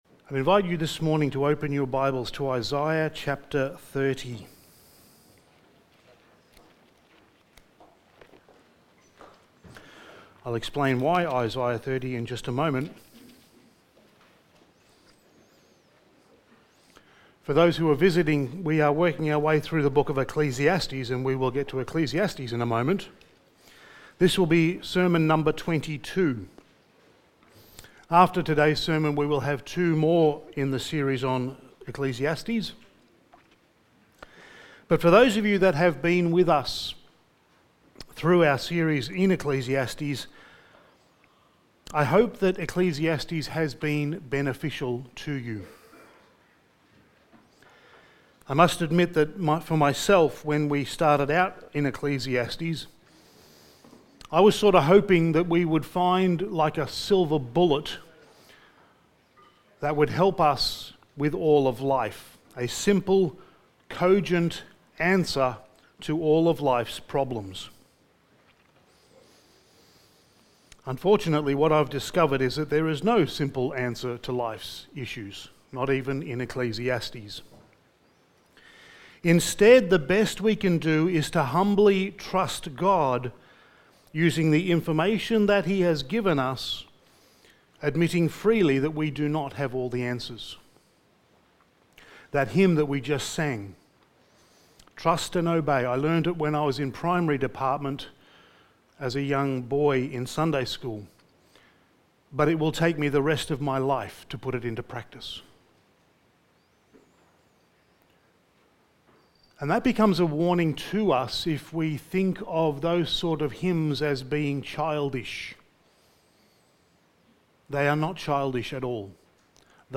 Ecclesiastes Series Passage: Ecclesiastes 11:1-6 Service Type: Sunday Morning « Studies in the Book of Ecclesiastes Sermon 21